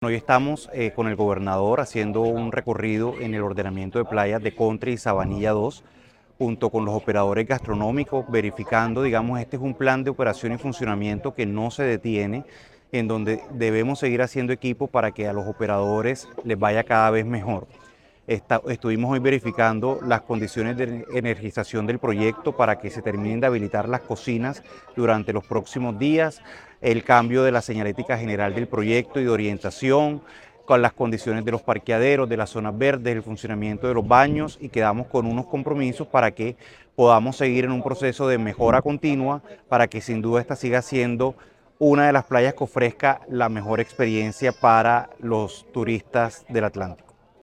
Jorge Ávila, gerente de Plazas y Parques Gobernación de Atlántico
Audio-Jorge-Avila-gerente-de-Plazas-y-Parques-Gobernacion-de-Atlantico-recorrido-playa-Sabanilla-sector-Country.mp3